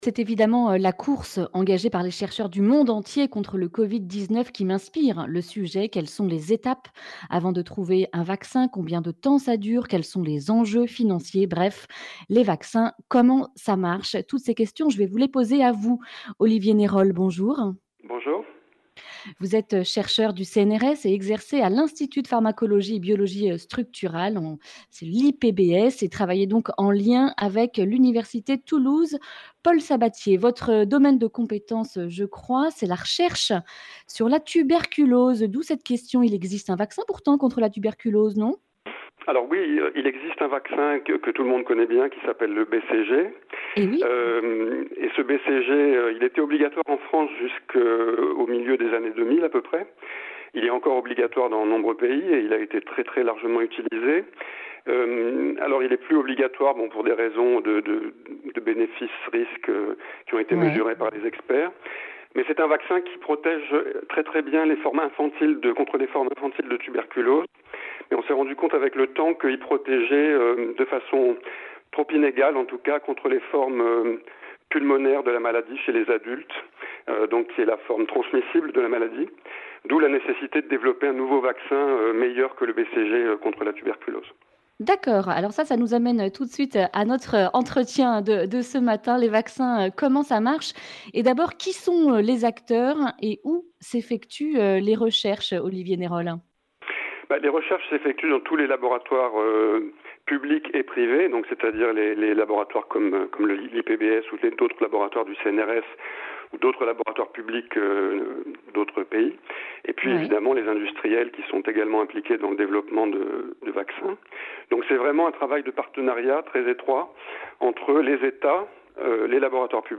Accueil \ Emissions \ Information \ Régionale \ Le grand entretien \ Les vaccins, comment ça marche ?